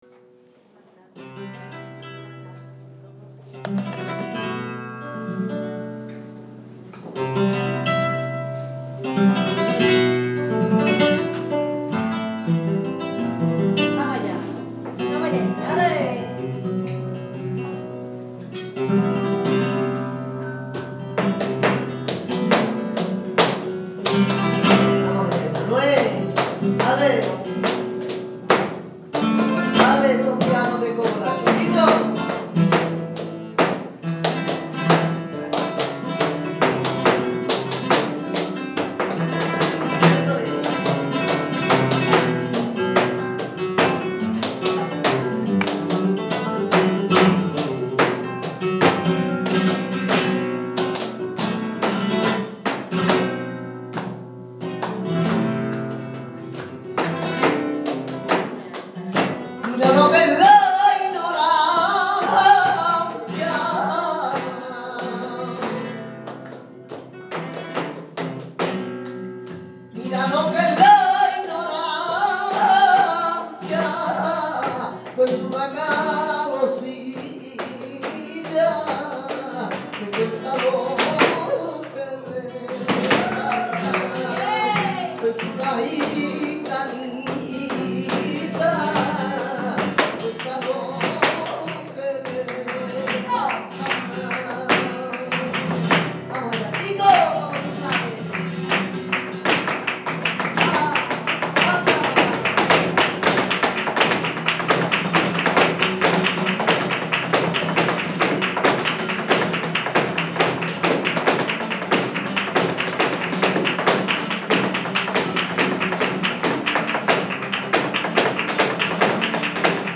ホテルでの夕食後、フラメンコショーを鑑賞しホテルへは深夜にお帰り。
フラメンコギターも熱のこもった演奏を行っていました。
フラメンコ・ギター演奏
framenco-music.mp3